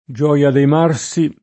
DOP: Dizionario di Ortografia e Pronunzia della lingua italiana